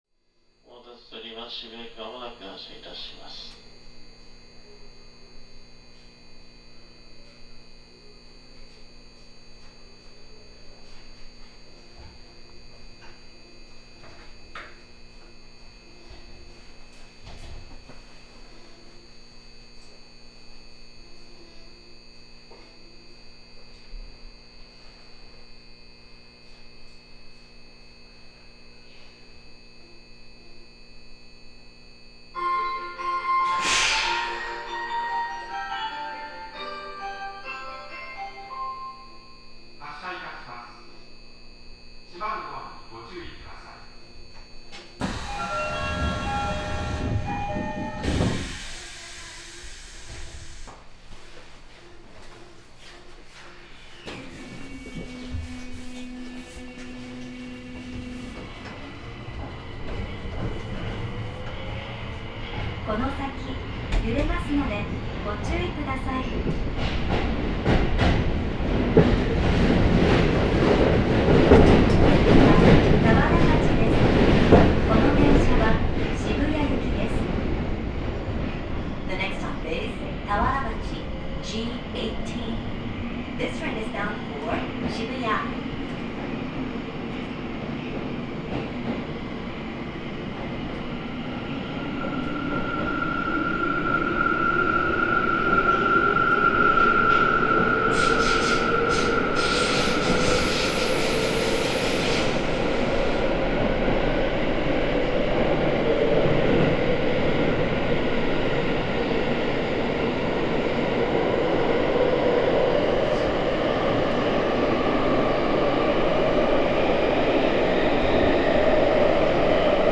東京地下鉄(東京メトロ) 1000系 ・ 第1～20編成以降走行音(全区間) (46.5MB★) 収録区間：銀座線 渋谷→浅草 制御方式：VVVFインバータ制御(東芝2レベルIGBT) ・ 第21編成以降走行音(全区間) (46.4MB★) 収録区間：銀座線 浅草→渋谷 制御方式：VVVFインバータ制御(東芝ハイブリッドSiC) 2012(平成24)年に登場した銀座線用の車両で、01系をすべて置き換えた。
モーター音は、制御装置が16000系同様に東芝製ということもあり、同車同様に低い音から高い音へ2段階に変化する非同期音が特徴となっている。ただし、主電動機が1台車1台ということもあって音は同車よりかなり小さく、空調が止まっていないとモーター音は全く聞こえない。